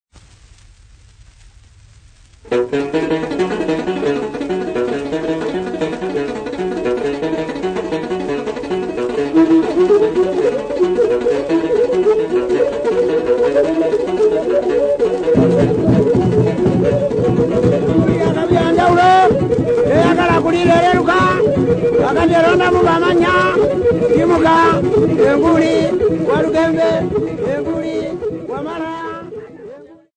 Folk Music
Field recordings
Africa Uganda Kampala f-ug
sound recording-musical
Indigenous music